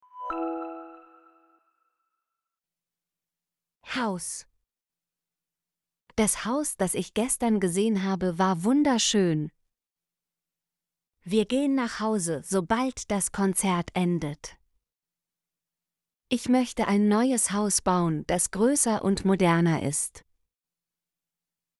haus - Example Sentences & Pronunciation, German Frequency List